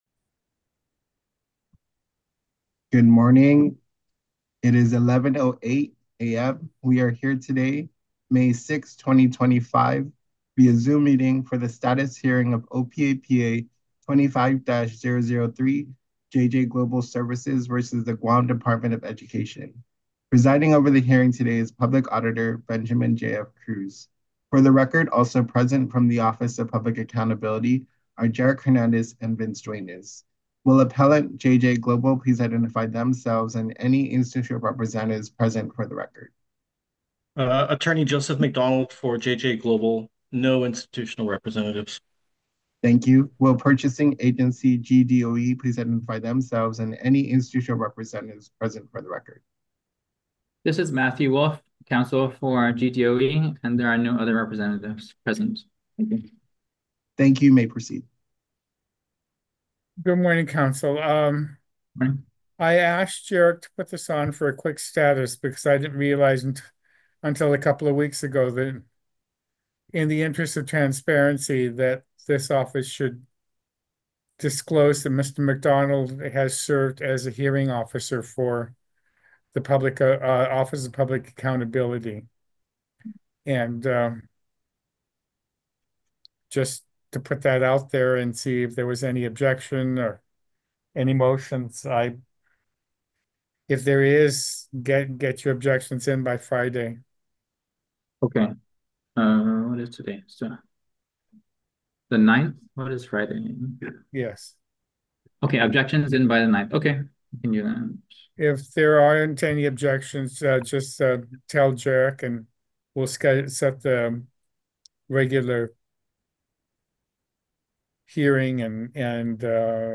Status Hearing - May 6, 2025